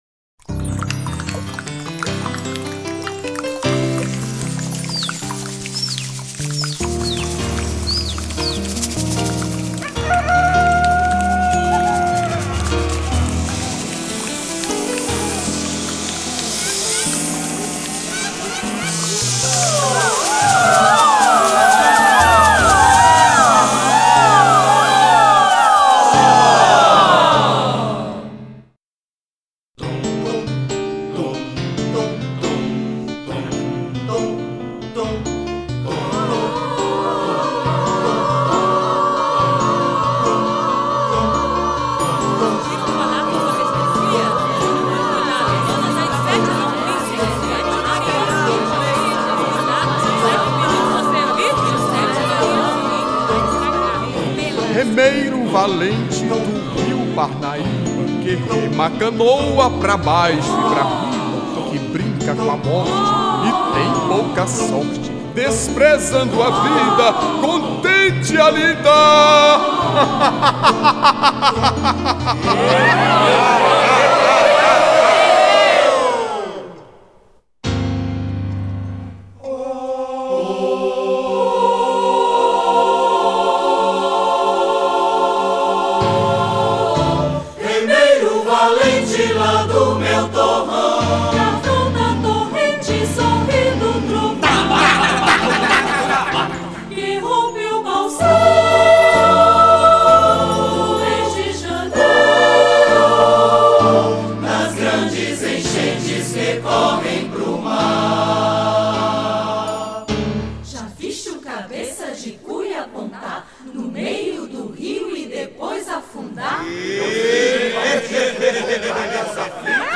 877   06:20:00   Faixa:     Folclore Piauiense